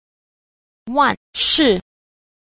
2. 萬事 萬物 – wànshì wànwù – vạn sự vạn vật
Cách đọc: